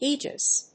音節ae・gis 発音記号・読み方/íːdʒɪs/